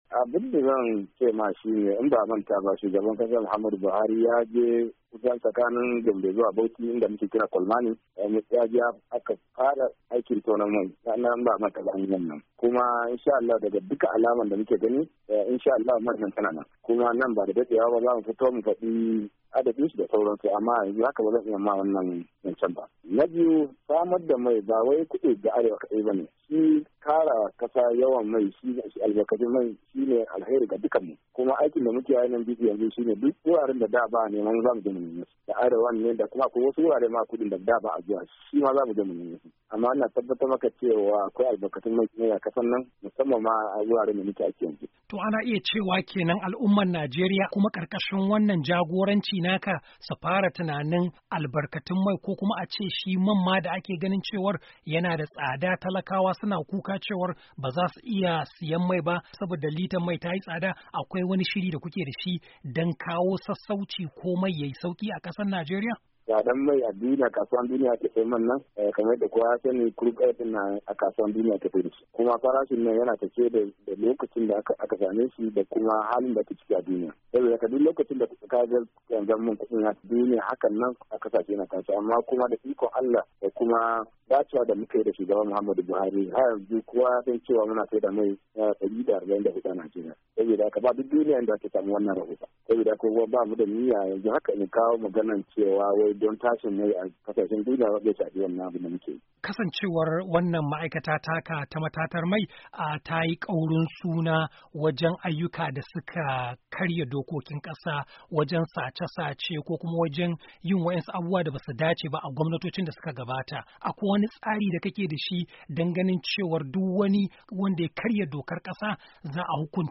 "Shugaban kasa ya ba mu ikon cewar duk inda mai yake a kasar nan mu nemo shi kuma mu fara dibar shi don sarrafawa da wadatar da kasar da mai mai rahusa ga talakawa." Inji Kyari yayin wata zantawa da ya yi Muryar Amurka.